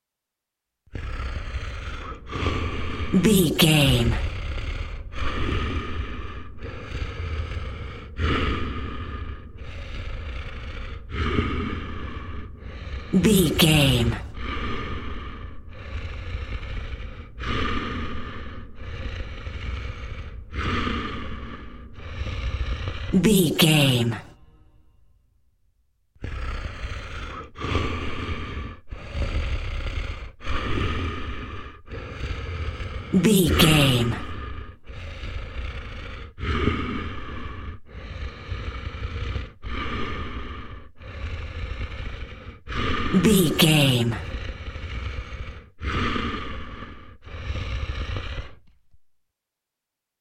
Monster rage breath rabid with without rvrb
Sound Effects
scary
ominous
disturbing
angry